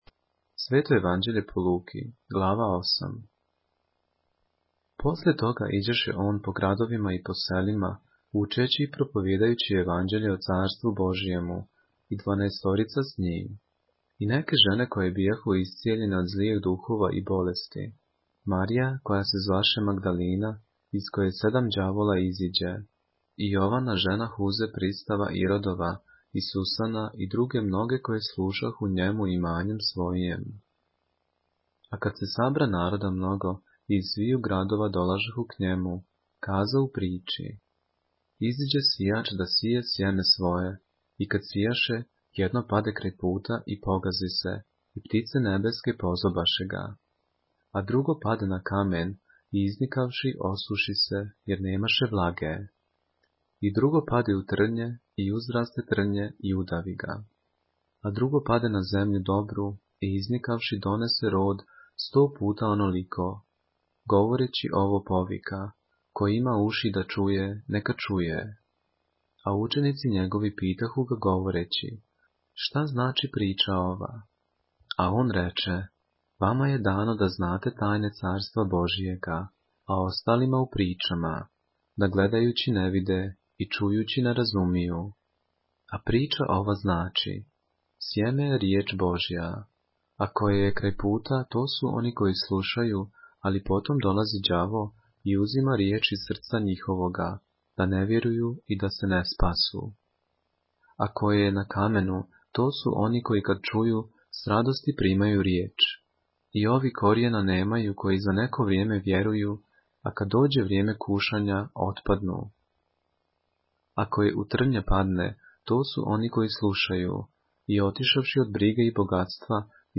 поглавље српске Библије - са аудио нарације - Luke, chapter 8 of the Holy Bible in the Serbian language